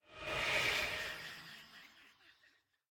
assets / minecraft / sounds / mob / vex / idle4.ogg